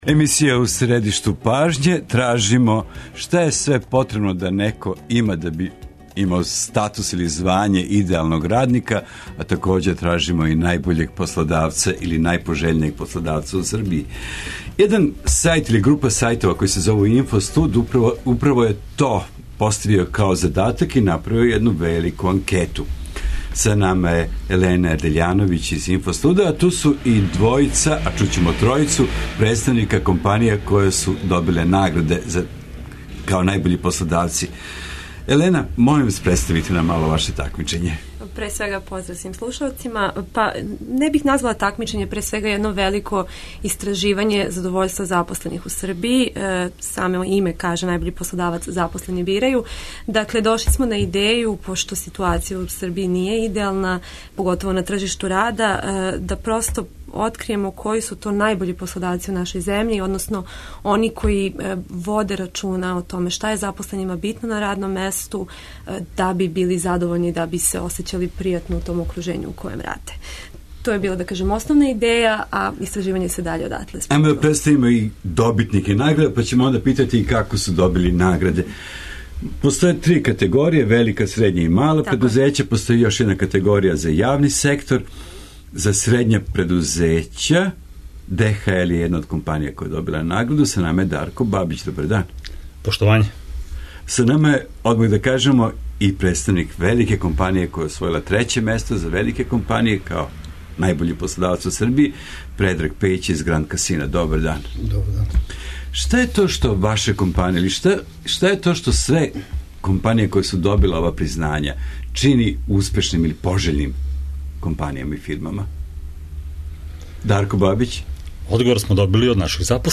У емисији У средишту пажње наши саговорници, из победничких компанија, говоре о томе како се ради на међуљудским односима у фирми, како се посао организује да би се дошло до што бољих резултата и како се постиже да су запослени задовољни зато што раде - баш то што раде.